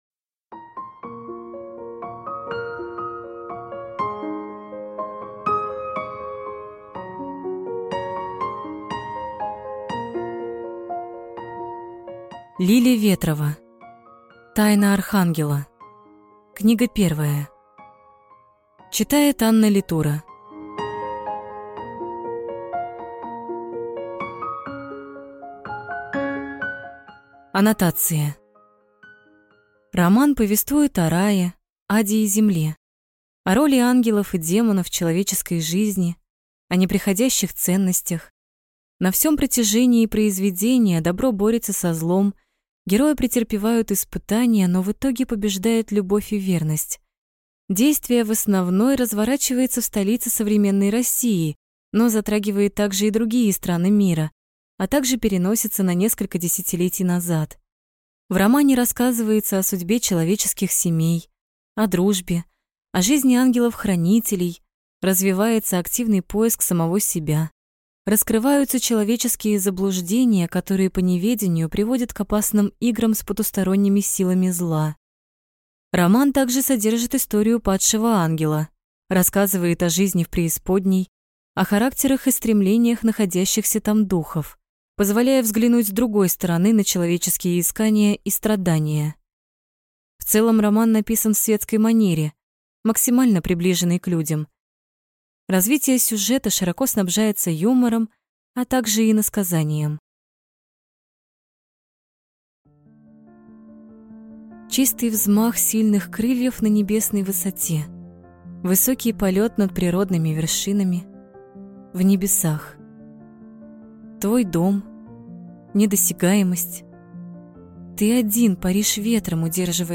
Аудиокнига Тайна архангела. Книга 1 | Библиотека аудиокниг